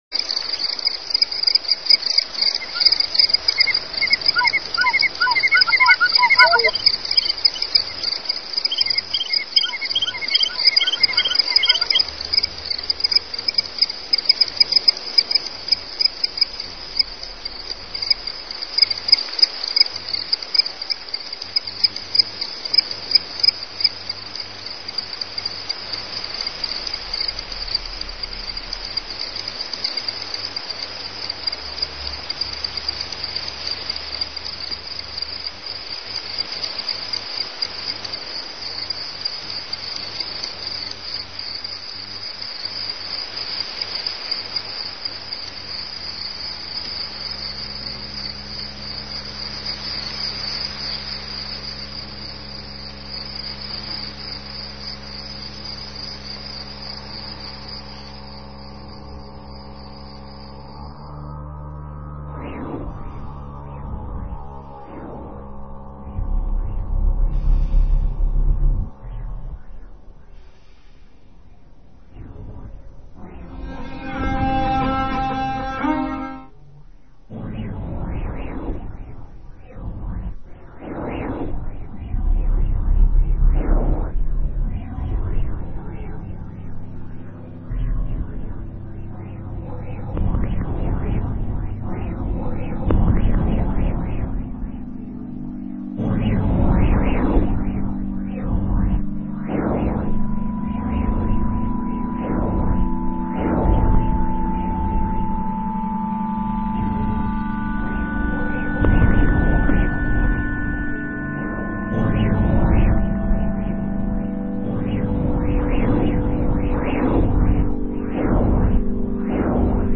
On Air and On line radio broadcast
A collage - live from ORF's studio 1